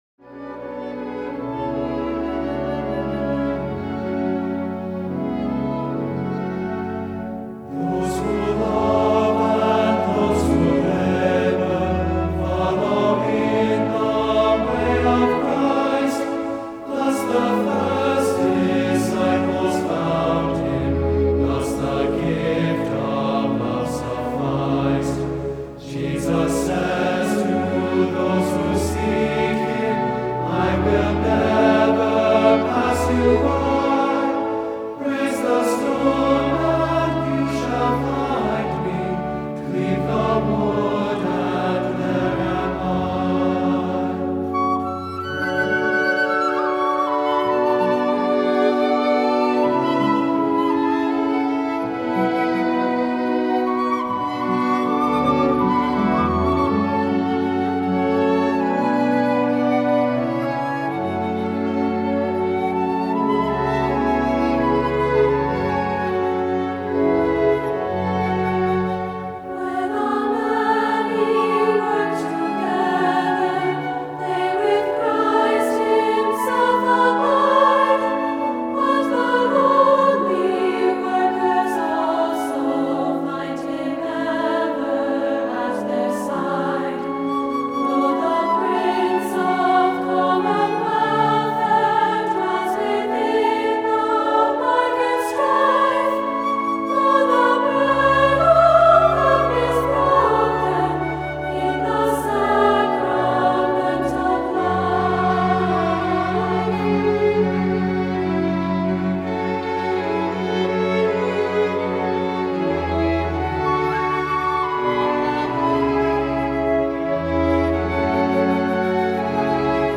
Voicing: 2-part Mixed